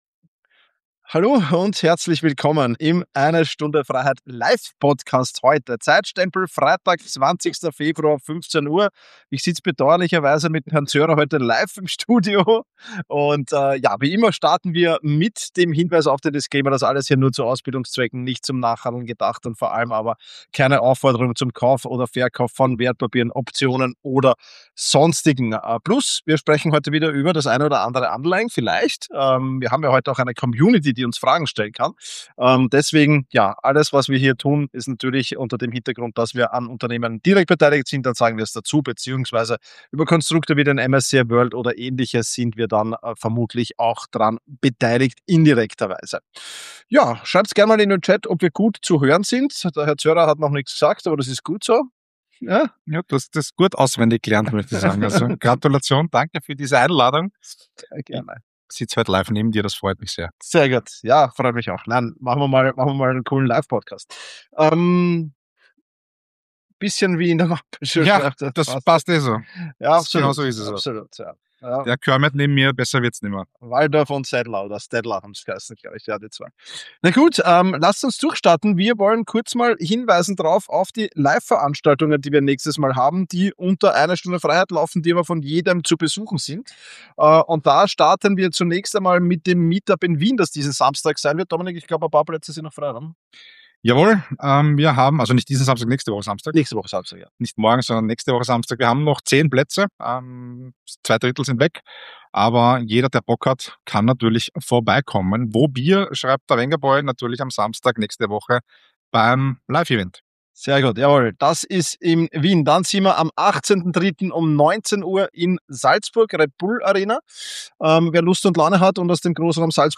Beide Hosts werfen einen kritischen Blick auf Themen wie Arbeitslosenzahlen, finanzielle Resilienz, Anleihen und Edelmetalle und teilen persönliche Einschätzungen dazu, was Anleger und Anlegerinnen in den kommenden Wochen erwarten dürfen. Freut euch auf faktenreiche Diskussionen, einen Hauch Selbstironie und jede Menge Insider-Tipps rund um Investitionen, Gesellschaft und technologische Trends.